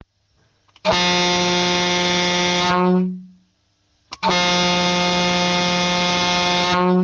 Fisa luchthoorn Gigant 65 4-8,5 bar118dB197Hz high tone182Hz low tone